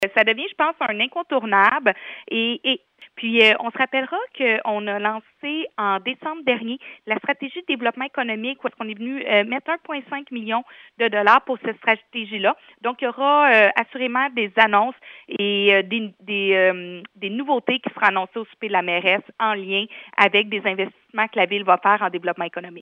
Écoutons la mairesse de Granby, Julie Bourdon : Granby, 3e edition souper mairesse, 27.02.25_Bourdon, clip Des échanges sont aussi prévus afin de répondre aux questions des participants.